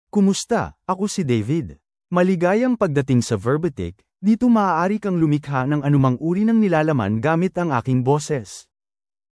MaleFilipino (Philippines)
David is a male AI voice for Filipino (Philippines).
Voice sample
Male
David delivers clear pronunciation with authentic Philippines Filipino intonation, making your content sound professionally produced.